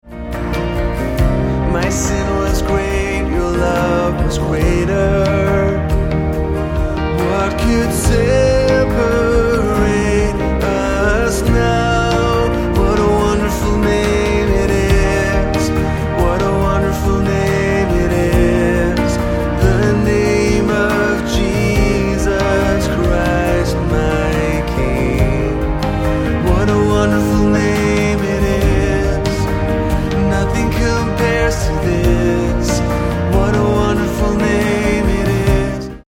Eb